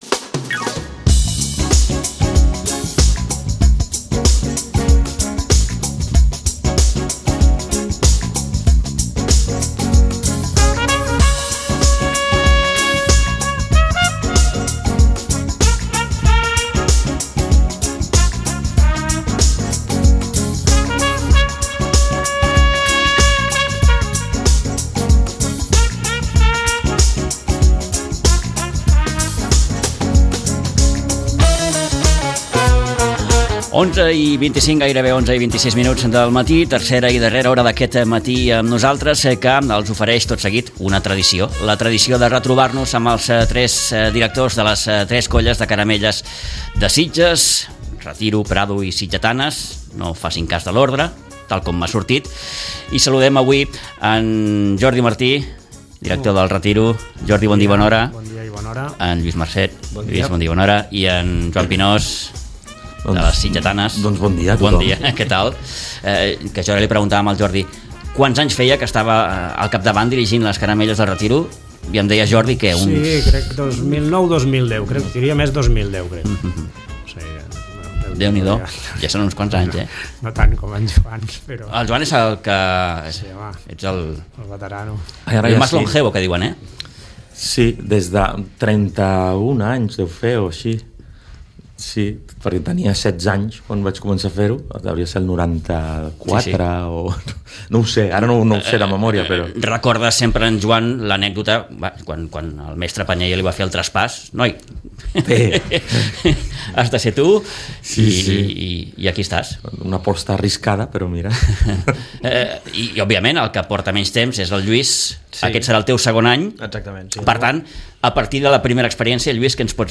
Una conversa que ja és un clàssic de cada any. La prèvia de les Caramelles i tot allò que les envolta amb la presència dels tres directors.